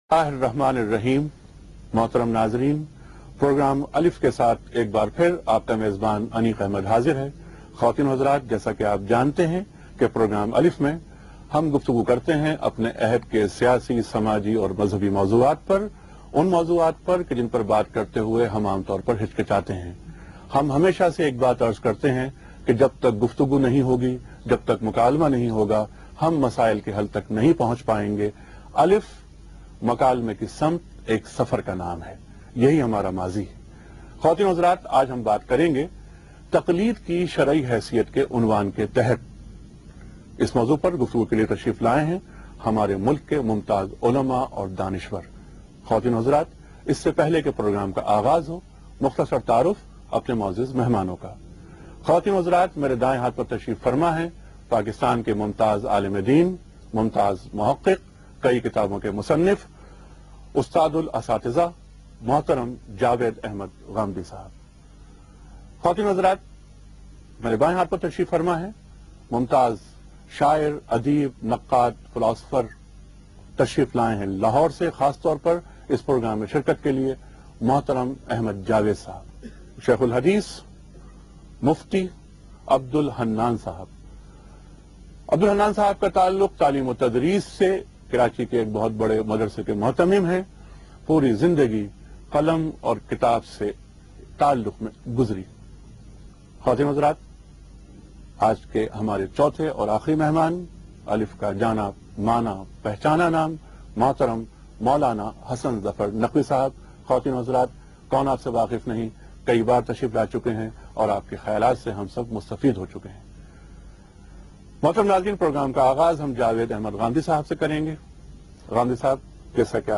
Category: TV Programs / Geo Tv / Alif /